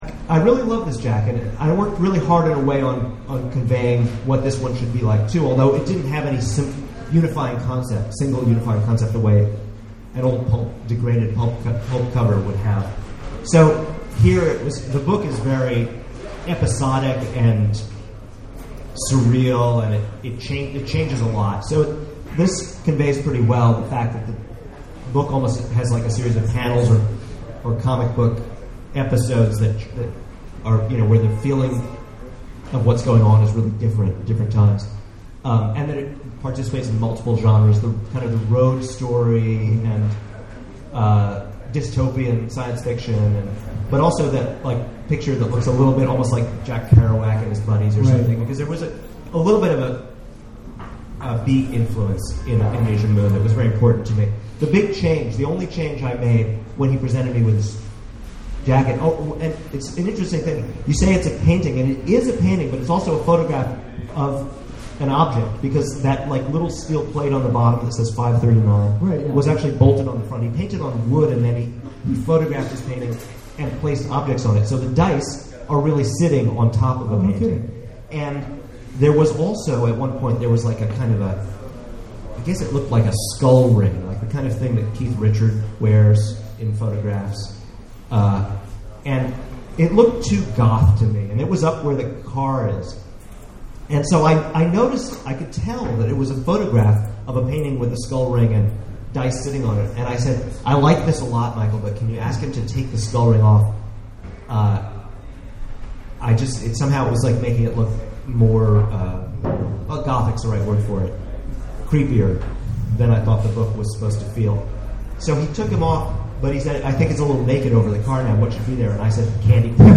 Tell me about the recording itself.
at The Last Bookstore in downtown Los Angeles for an in-depth discussion of his book covers.